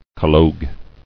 [col·logue]